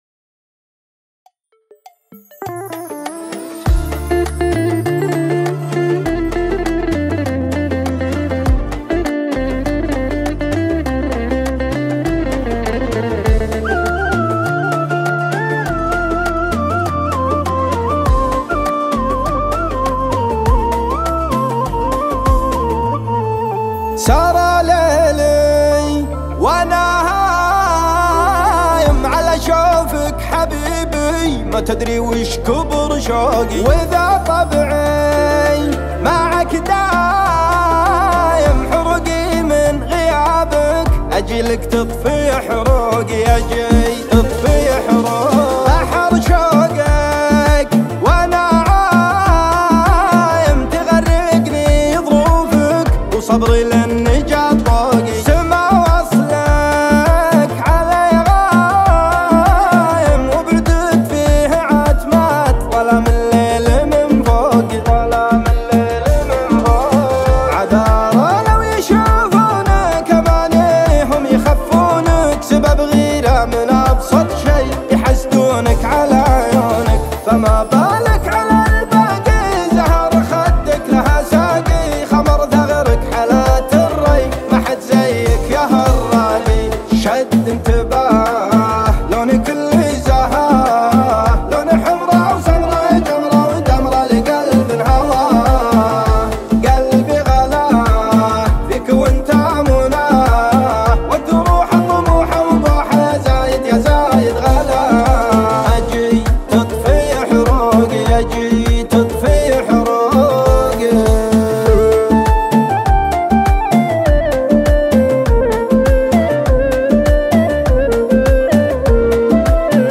شيلات حزينة